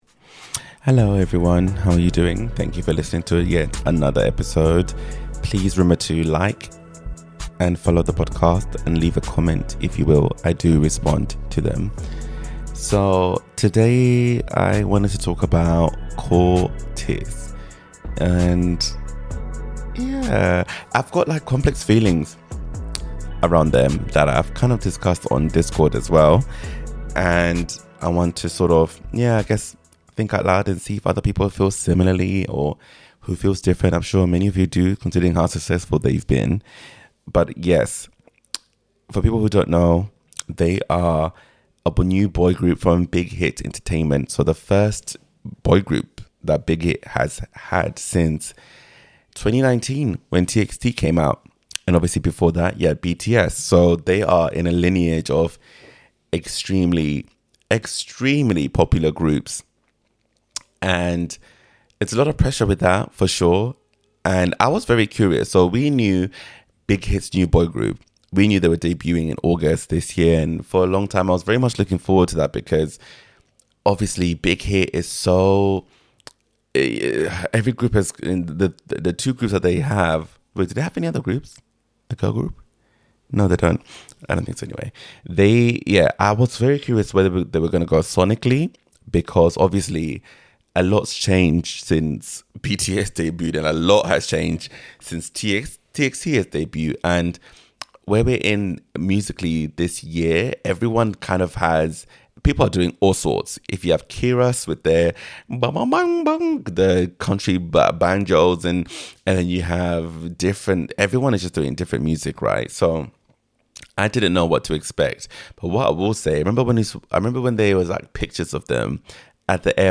With song clips and way too much autotune analysis, I’m unpacking why their music sounds so polished yet strangely familiar ...